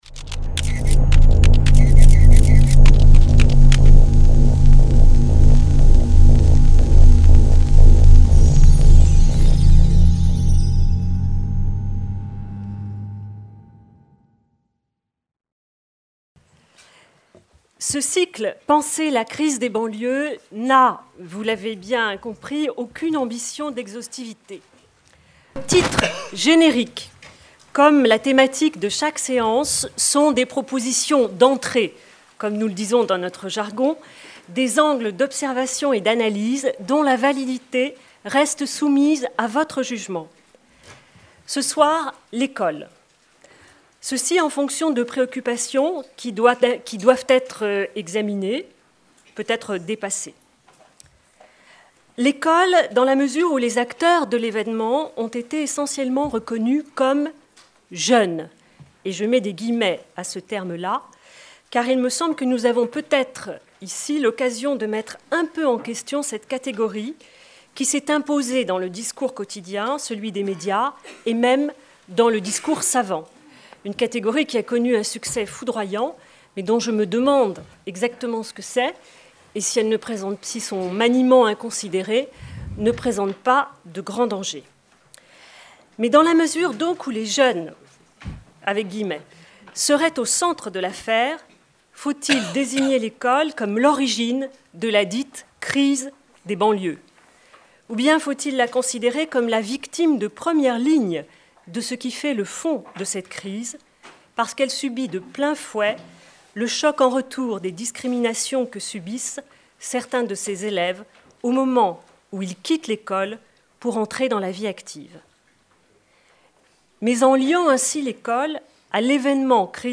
Les Éditions de l'EHESS Débats enregistrés du lundi 23 janvier 2006 au samedi 28 janvier 2006. Après le premier moment de commentaire à chaud de l'événement, l'EHESS a souhaité contribuer à établir une véritable circulation d'idées entre les acteurs sociaux et les chercheurs en sciences sociales, sociologues, économistes, anthropologues, historiens. 1 - Une société inégalitaire.